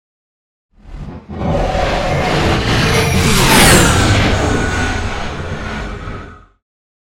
Scifi whoosh pass by long
Sound Effects
futuristic
high tech
intense
pass by